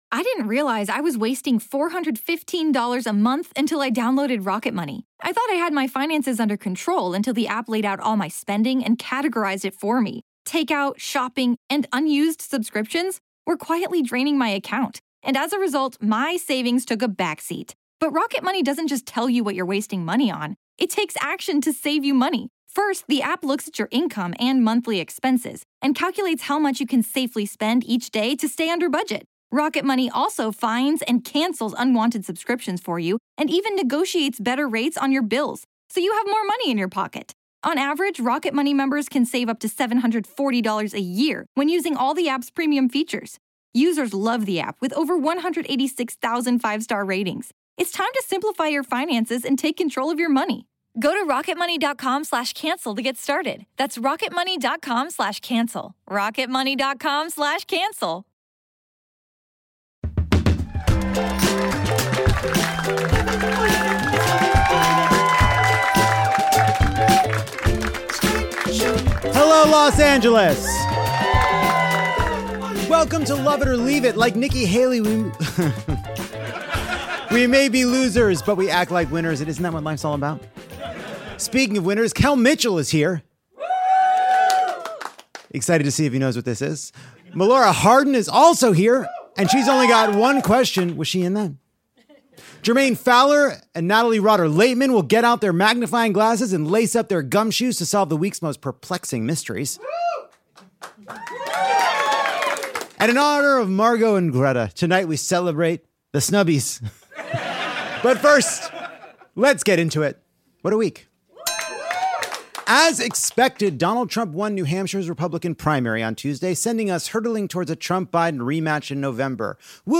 Melora Hardin interrogates the audience with a round of Was I In This?